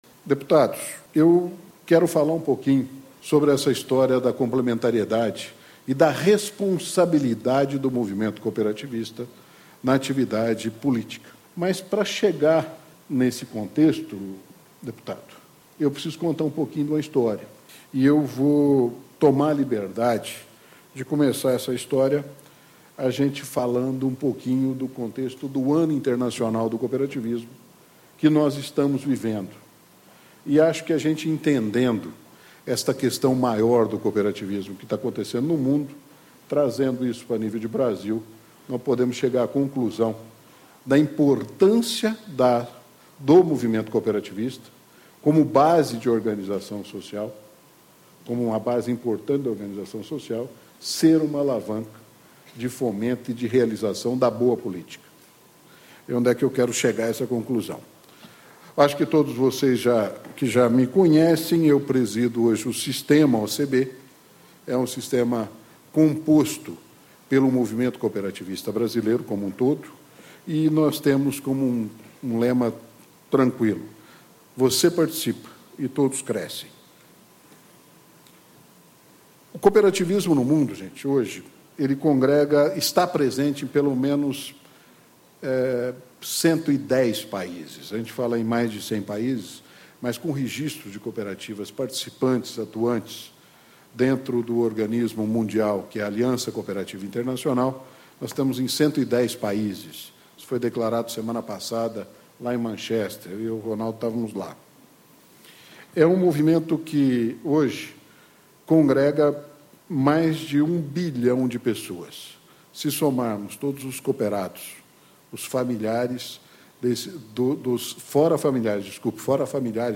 Ciclo de Debates Cooperar 2012 - Ano Internacional das Cooperativas
Discursos e Palestras